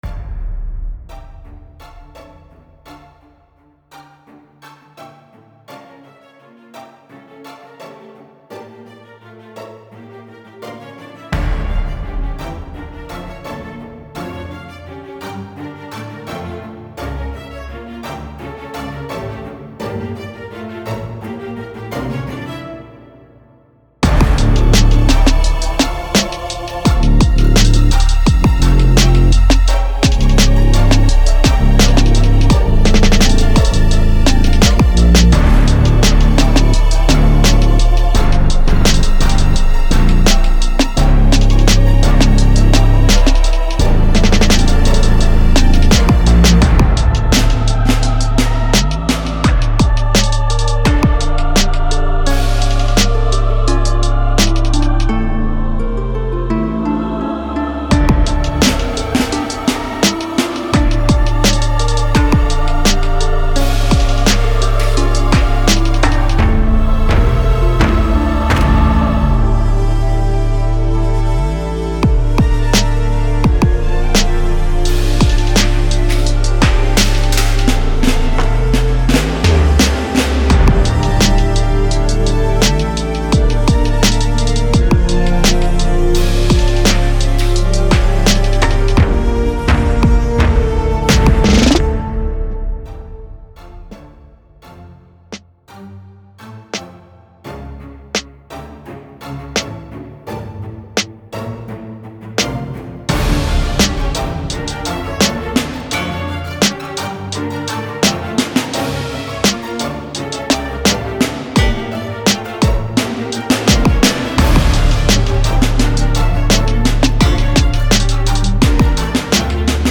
Ich bin ein erfahrener Hiphop/Trap Beat Maker aus Freiburg.